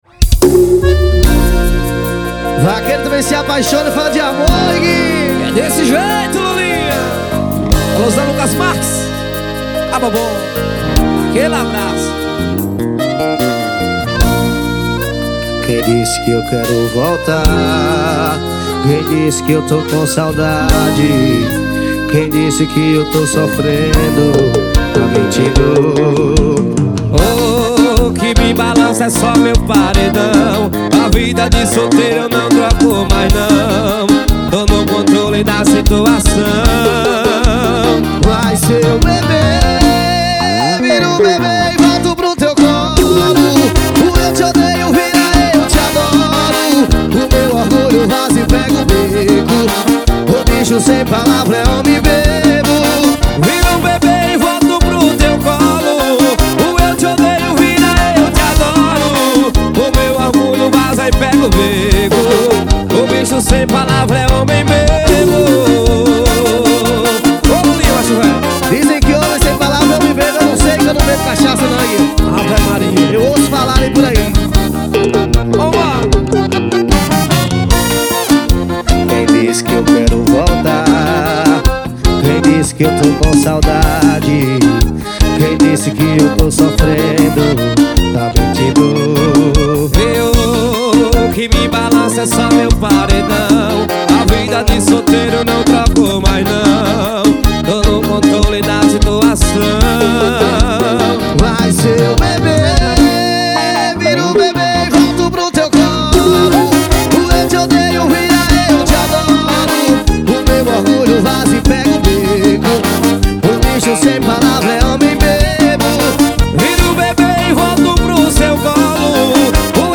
2024-02-14 18:17:42 Gênero: Forró Views